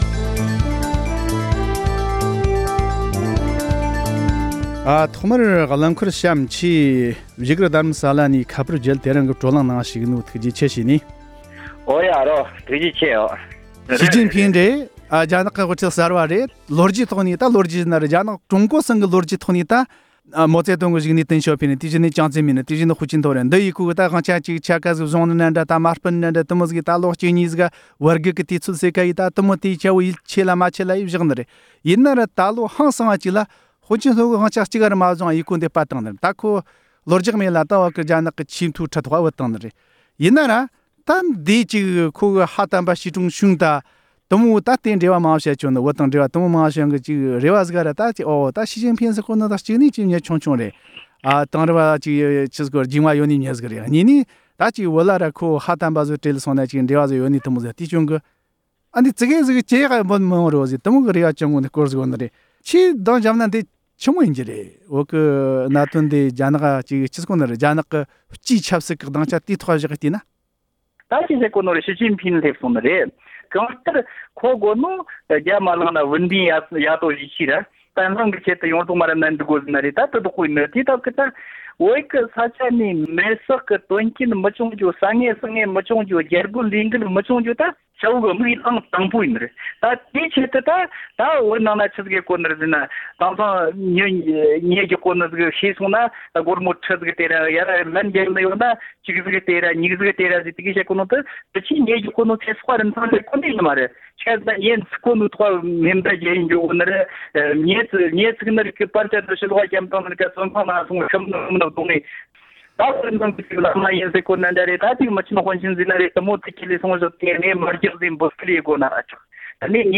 ཞི་ཅིན་ཕིང་གཞུང་གིས་བོད་ལ་ལྷོད་ཡངས་ཀྱི་སྲིད་ཇུས་འཛིན་མིན་སོགས་ཀྱི་ཐད་བགྲོ་གླེང༌།
སྒྲ་ལྡན་གསར་འགྱུར།